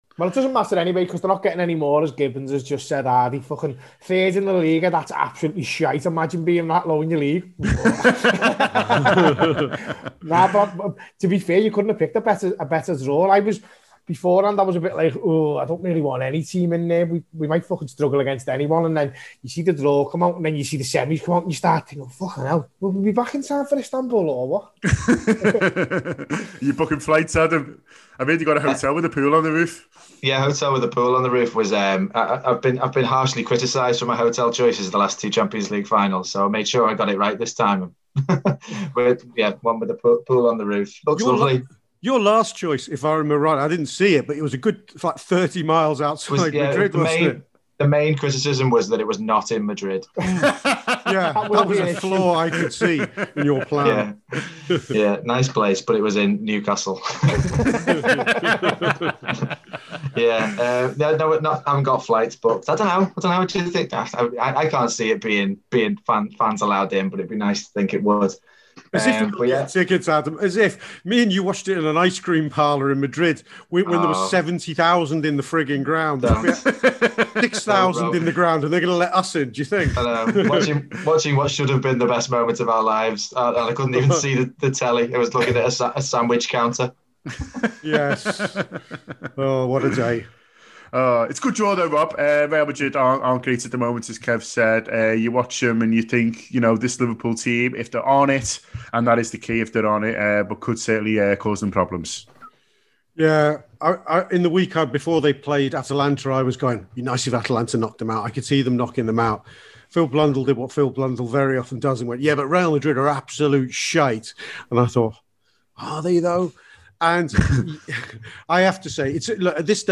The panel also react to the second half of the draw which dictated that the winner of that tie will face the winner of Chelsea v Porto in the semi final for a place in the Champions League final in Istanbul.